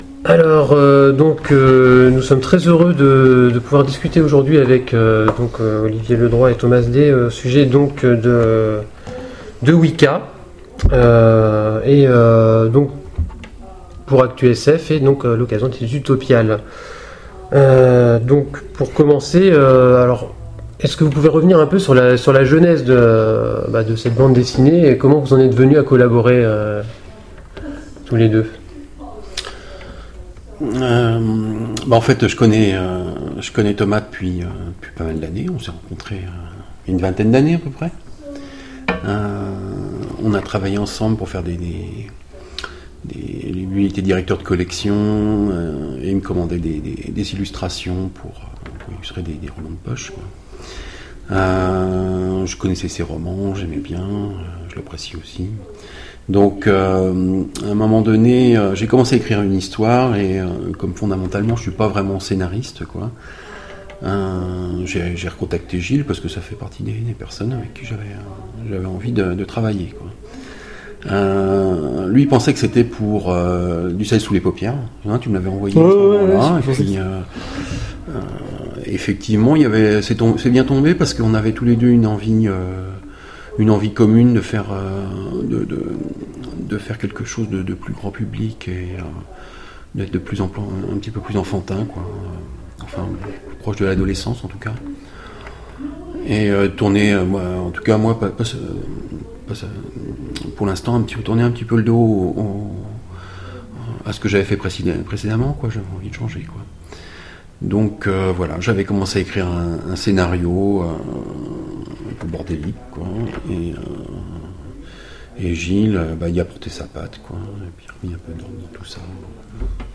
Mots-clés Interview Partager cet article